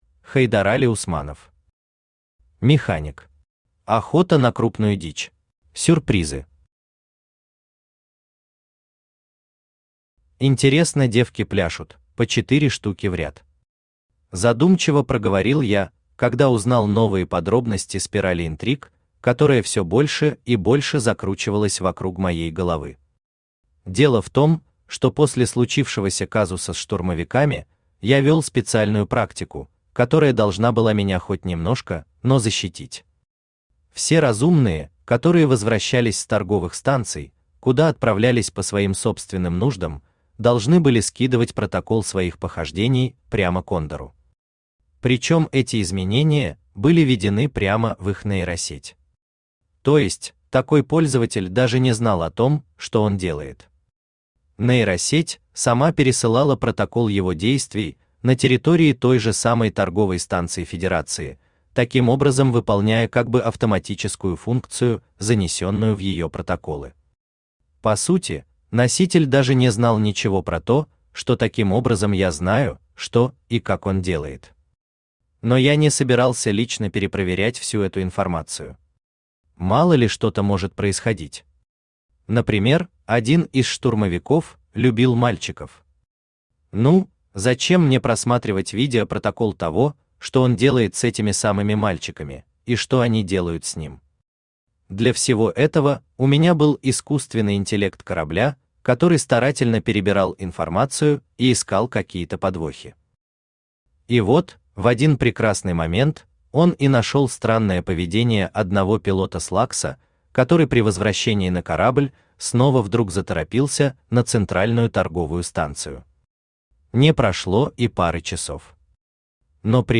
Аудиокнига Механик. Охота на крупную дичь | Библиотека аудиокниг
Охота на крупную дичь Автор Хайдарали Усманов Читает аудиокнигу Авточтец ЛитРес.